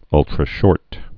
(ŭltrə-shôrt)